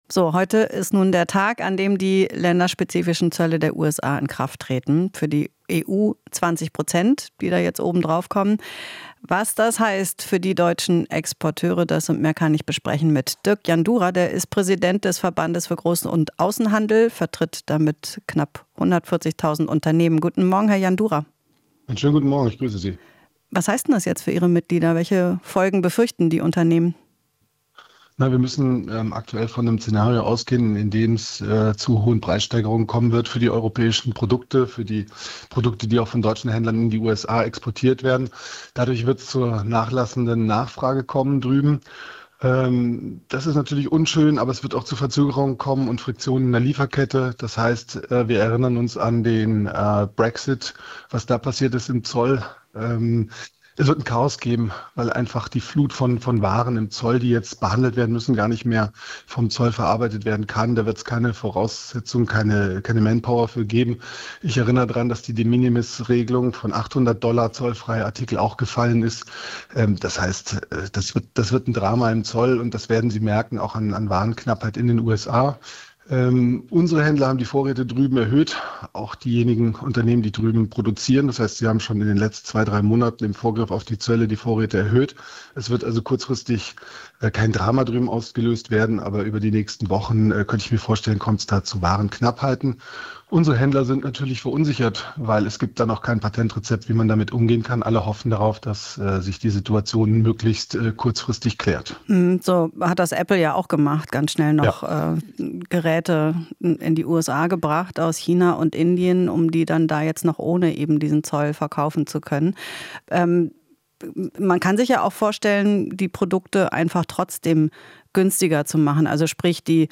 Interview - US-Zölle in Kraft: Außenhandel befürchtet weitreichende Folgen